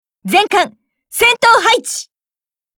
Cv-30401_warcry.mp3